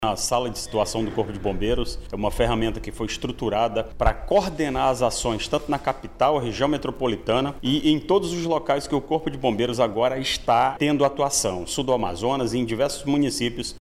O coronel Orleilso Muniz destaca, ainda, que a “Sala de Situação” vai atuar em todo o Estado.